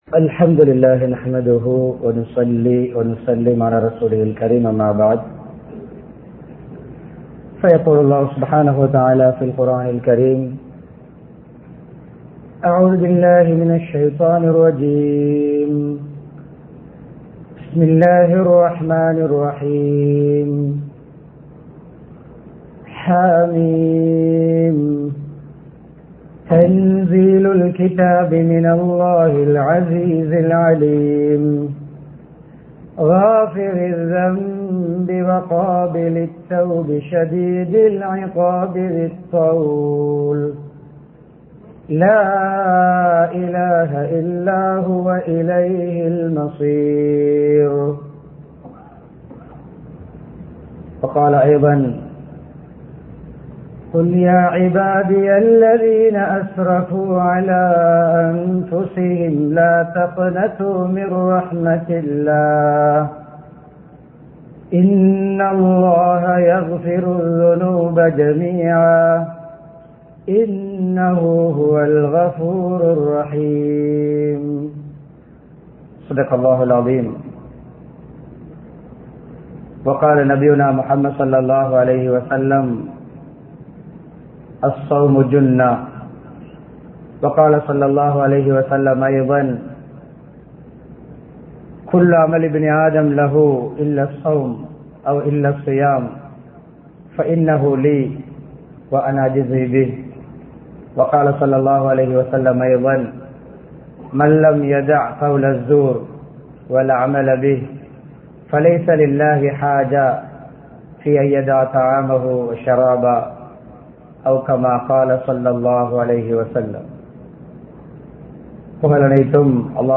ரமழானும் ஆன்மீக வளர்ச்சியும் | Audio Bayans | All Ceylon Muslim Youth Community | Addalaichenai
Colombo 12, Peer Sahib Street Ihsaniyyah Jumuah Masjith